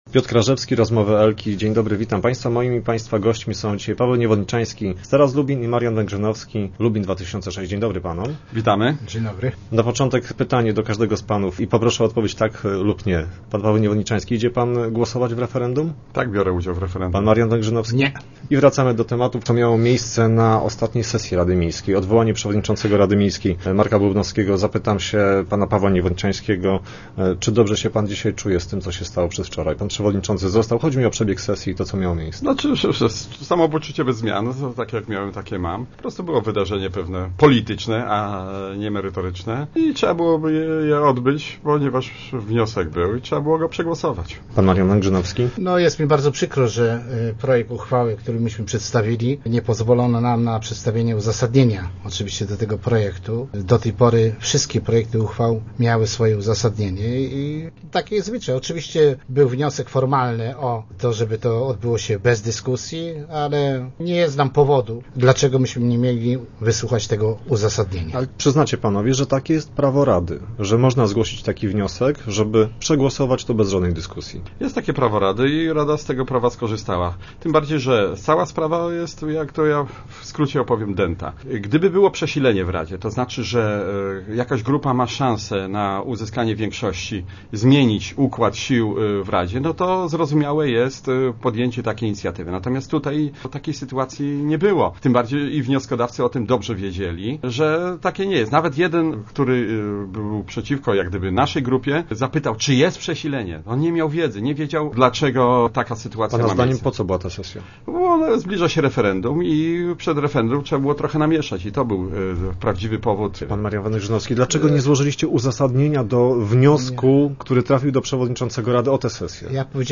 Paweł Niewodniczański i Marian Węgrzynowski, polityczni przeciwnicy mówili o tym w Rozmowach Elki.